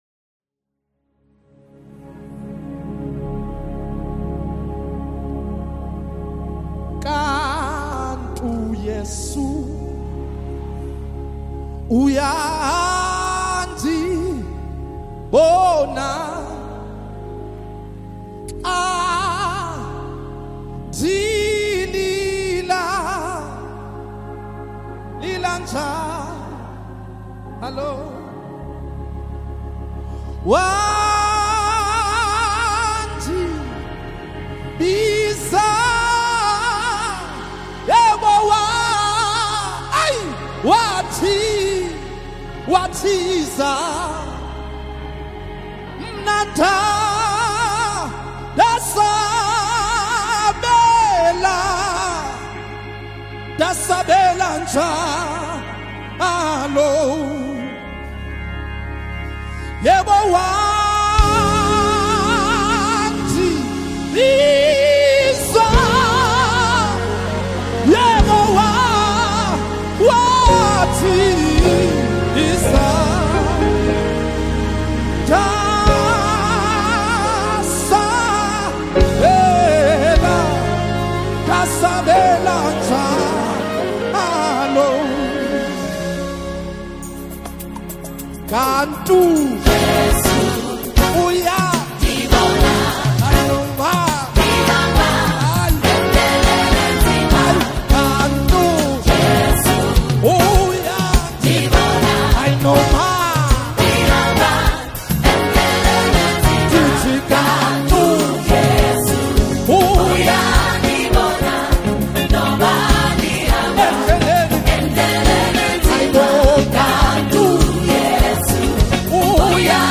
Latest South African Deep Worship Song